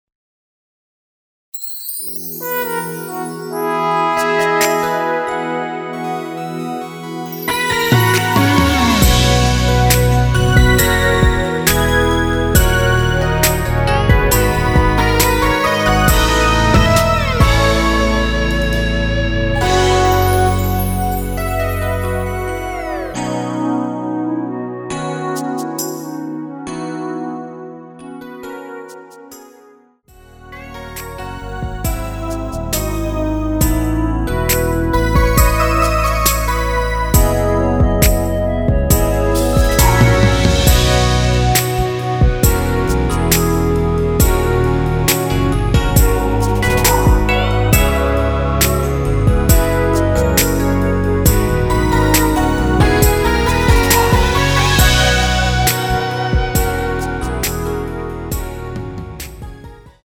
원키에서(-8)내린 (짧은편곡) 멜로디 포함된 MR입니다.
앞부분30초, 뒷부분30초씩 편집해서 올려 드리고 있습니다.
중간에 음이 끈어지고 다시 나오는 이유는